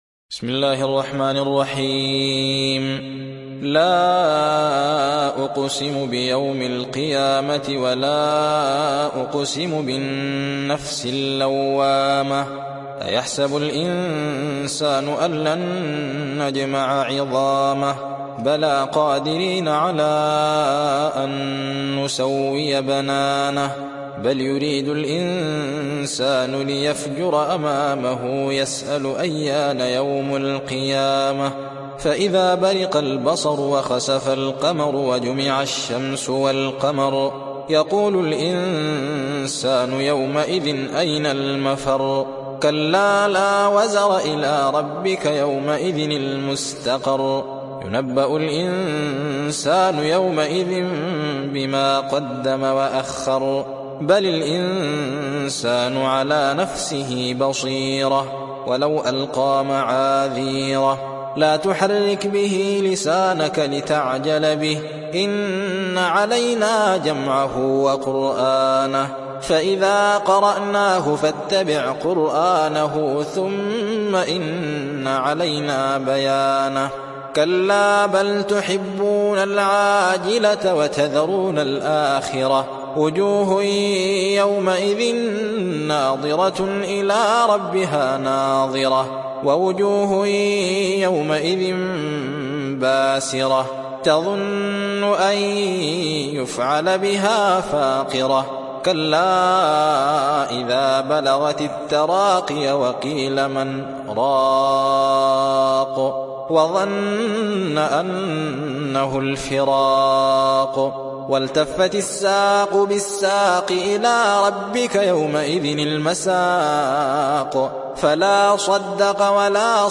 دانلود سوره القيامه mp3 الزين محمد أحمد روایت حفص از عاصم, قرآن را دانلود کنید و گوش کن mp3 ، لینک مستقیم کامل